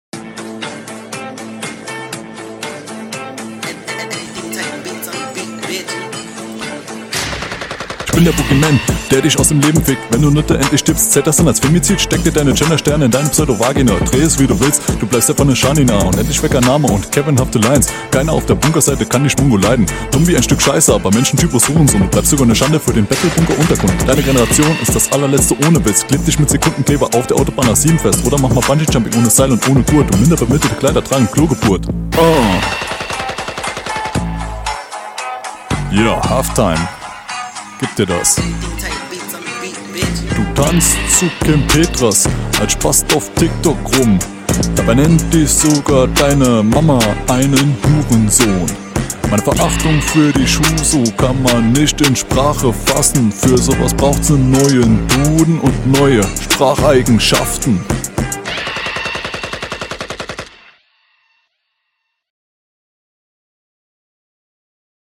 Flow ist hier direkt ein großer Step Up.